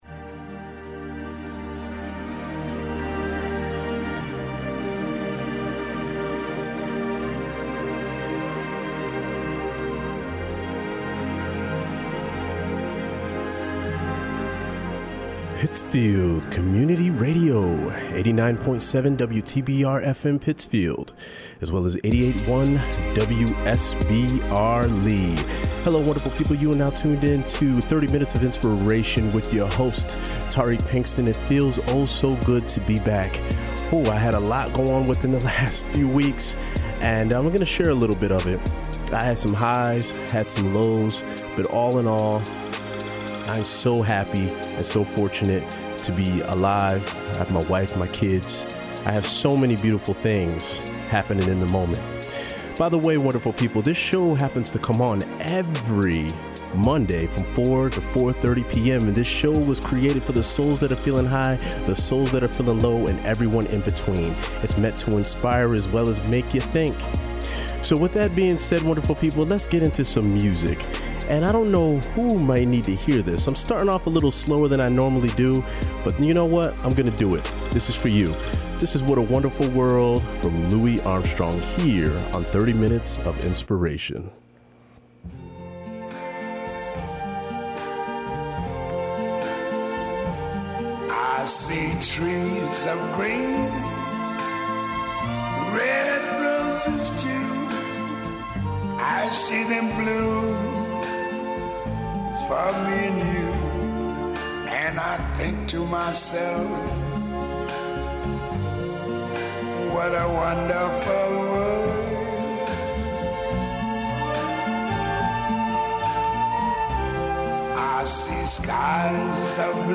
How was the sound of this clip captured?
broadcast live every Monday afternoon at 4pm on WTBR.